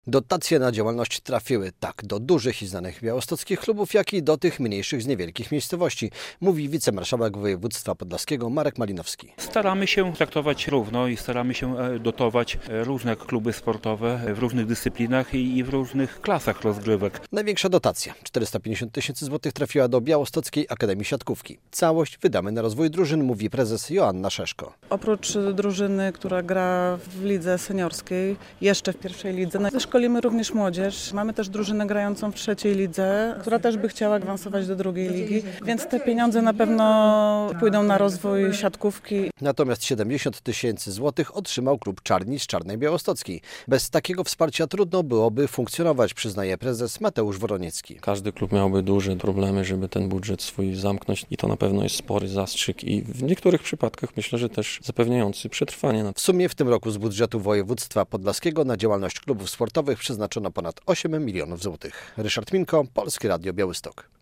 Dotacje dla podlaskich klubów z budżetu województwa - relacja